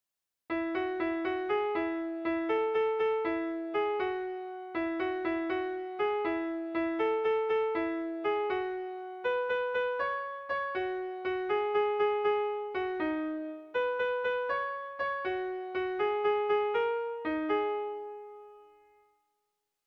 Erlijiozkoa
Zortziko txikia (hg) / Lau puntuko txikia (ip)
AAB1B2